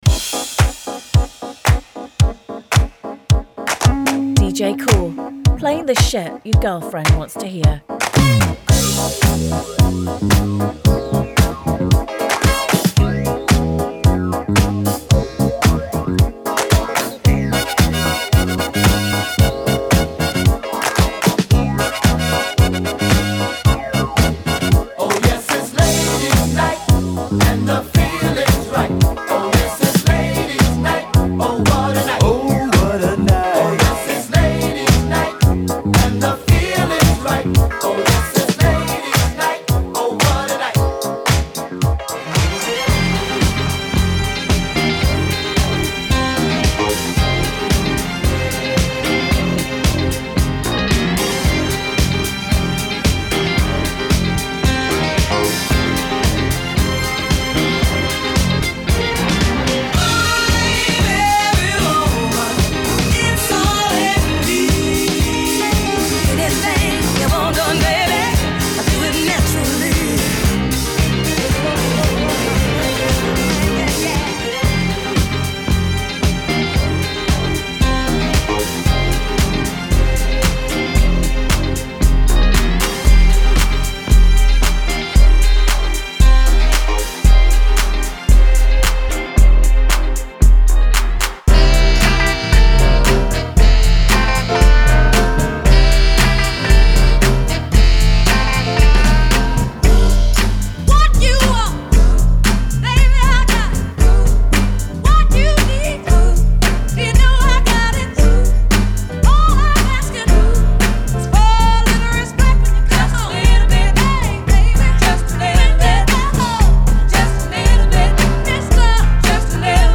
open format mix